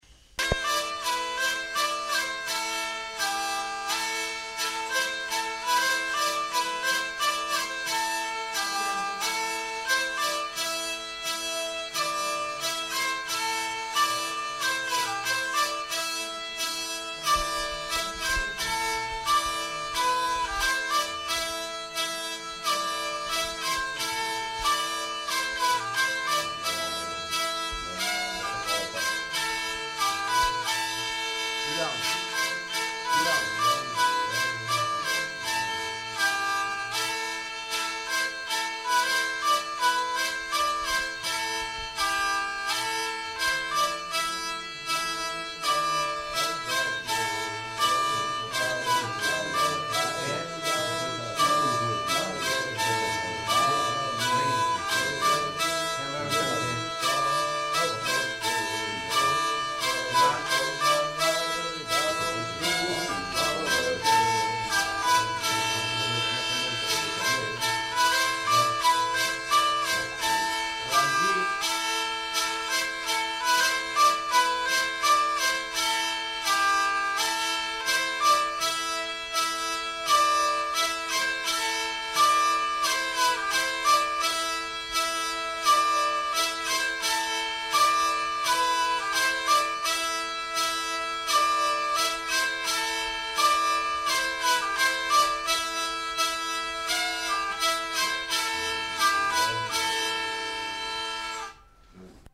Lieu : Pyrénées-Atlantiques
Genre : morceau instrumental
Instrument de musique : vielle à roue
Notes consultables : Situation d'apprentissage. Un homme chante à un moment donné mais on ne l'entend pas bien.